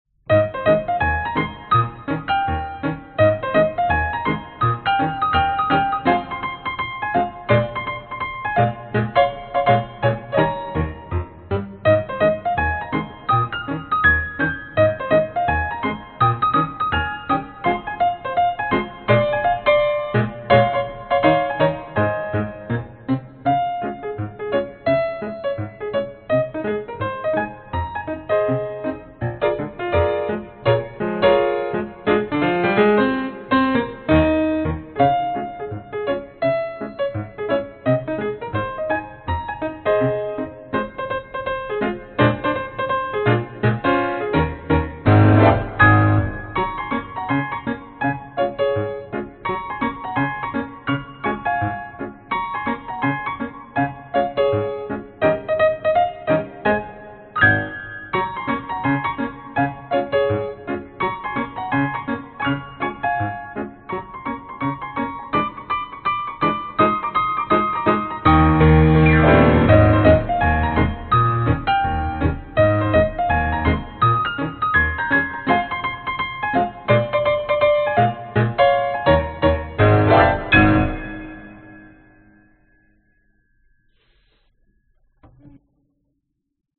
描述：这是一场雨声。它是用淋浴，风扇，水杯灌装等创造的。没有雷声。
Tag: 环境光 湿 有机 城市 天气 雨滴